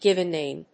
アクセントgíven náme
音節gìven náme